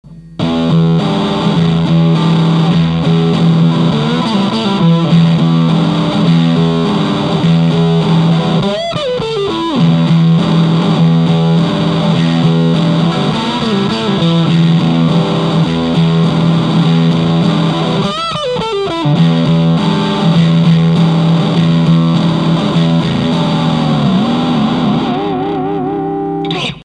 硬くザラついた感じの音になりました。